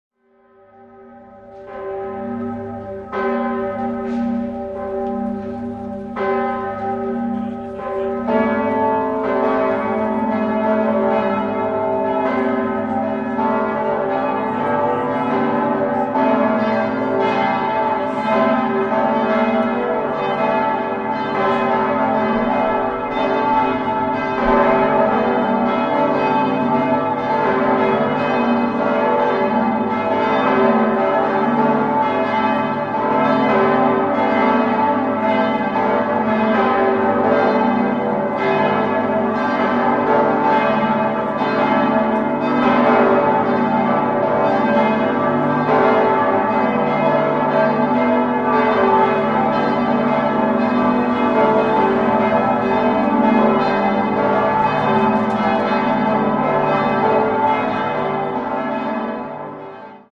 6-stimmiges ausgefülltes und erweitertes G-Dur-Geläute: g°-a°-h°-d'-e'-g'
bell